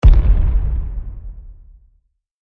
explode.mp3